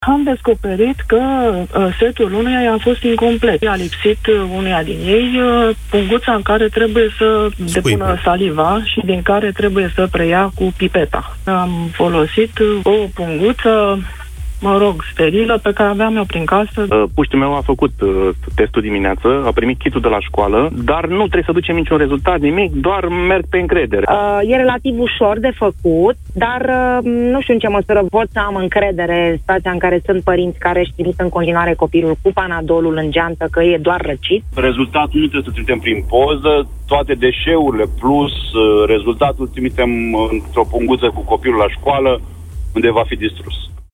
Cele mai multe teste se fac acasă, iar mai mulți ascultători au povestit în emisiunea Deșteptarea, experiența pe care au avut-o copiii lor dimineață.